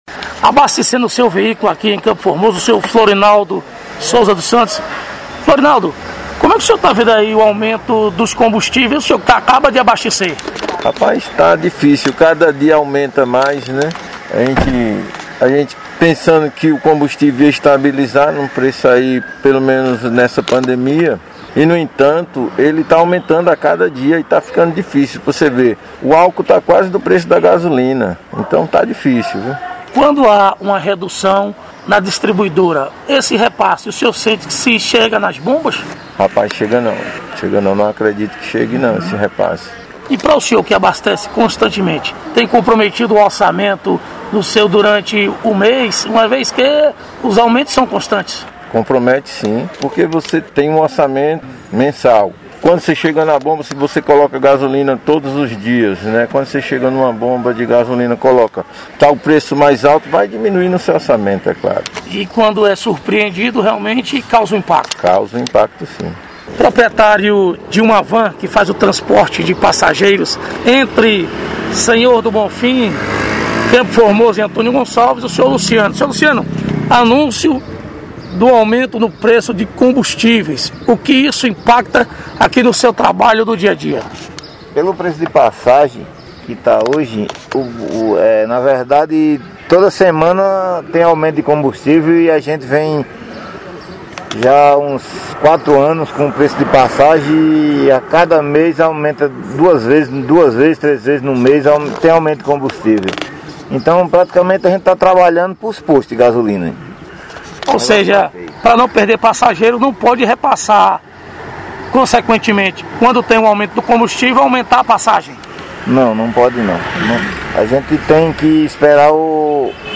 Reportagem: preço dos combustíveis